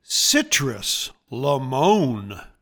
Pronounciation:
CI-trus li-MON